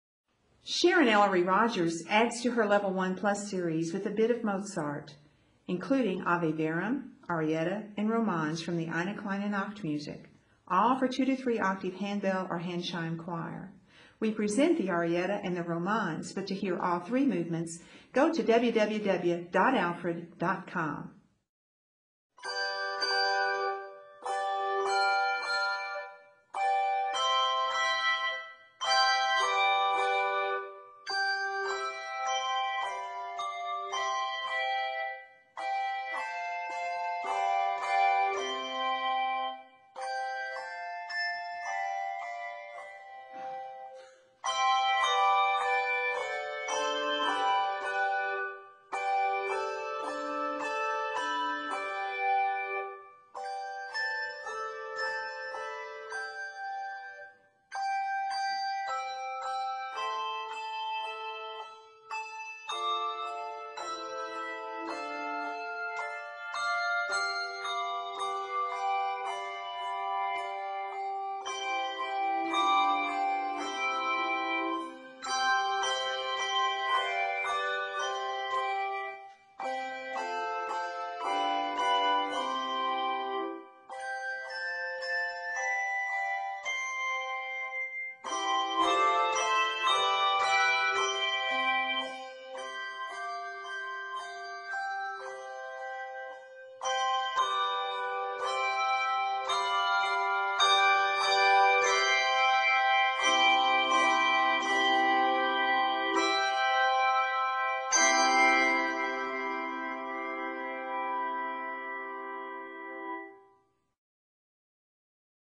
2 to 3-octave handbell or handchime choir